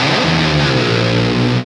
Index of /90_sSampleCDs/Roland L-CDX-01/GTR_GTR FX/GTR_Gtr Hits 1
GTR DIVE 06L.wav